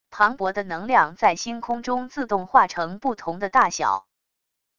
磅礴的能量在星空中自动化成不同的大小wav音频